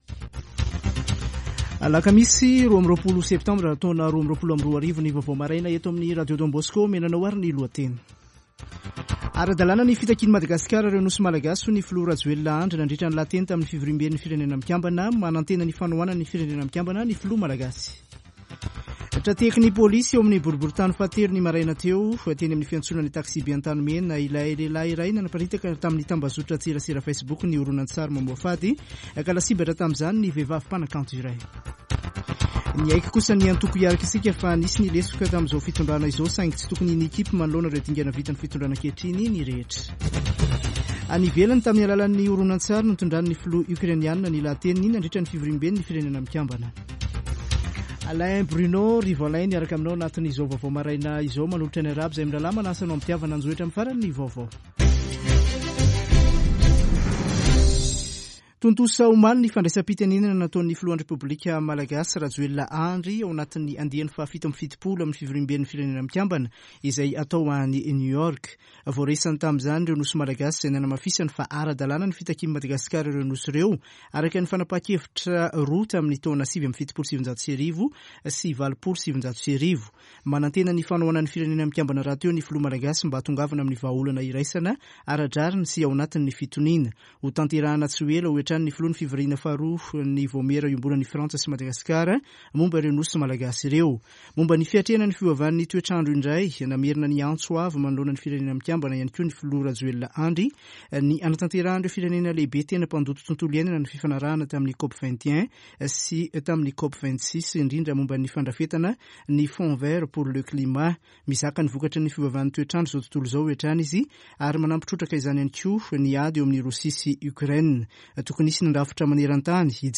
[Vaovao maraina] Alakamisy 22 septambra 2022